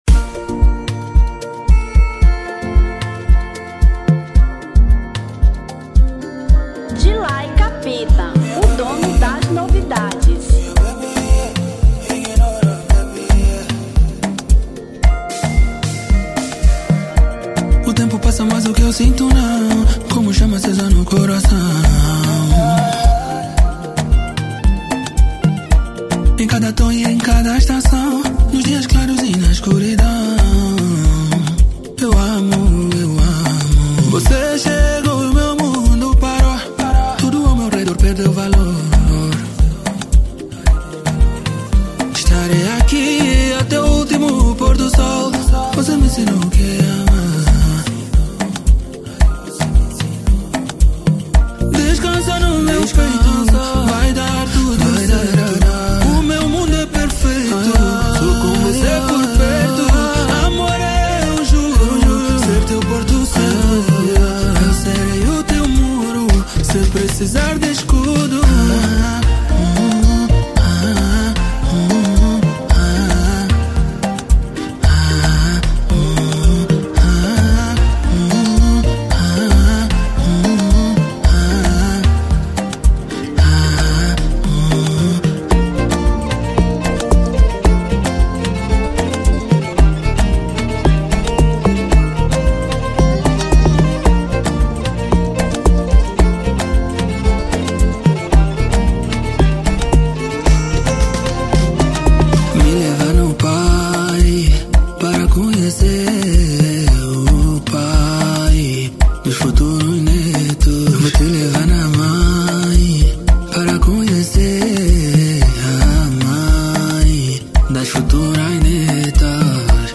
Afro Pop 2025